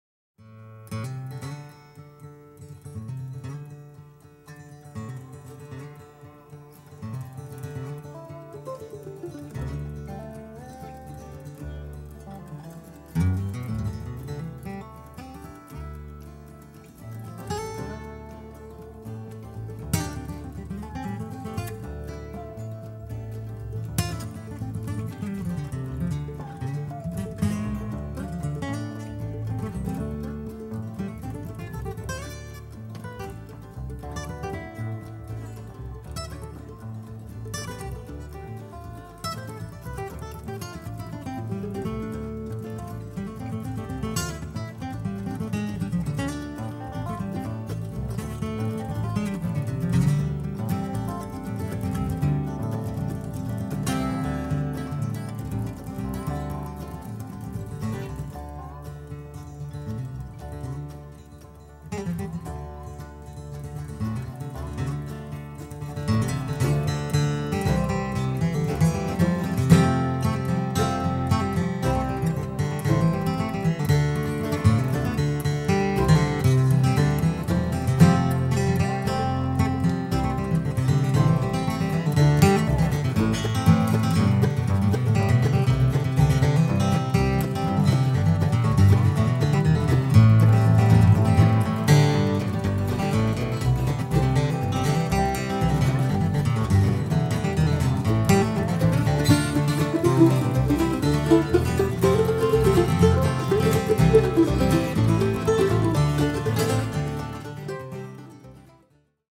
SHADY GROVE | GUITAR